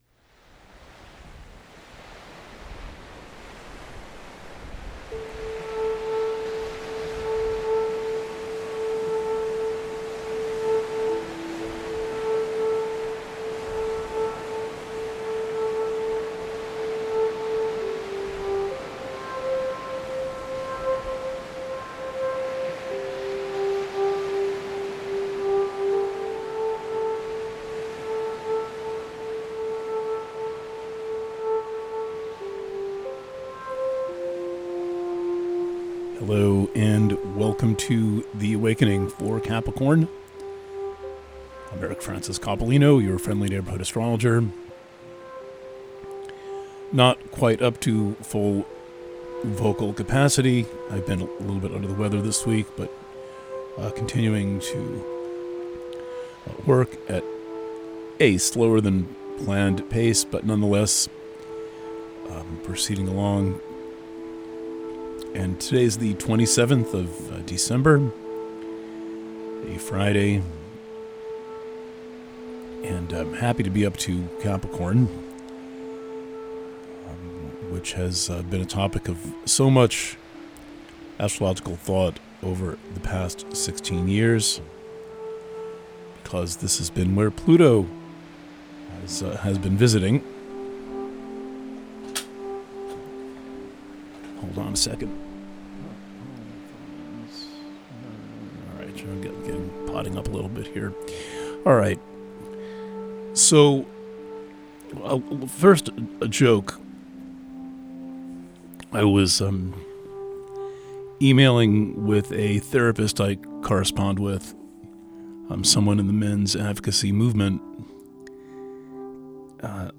Purchasing options for The Awakening Preview - Written reading Preview - The Awakening for Capricorn - PDF Preview - Audio reading Alternate Player (Audio Only)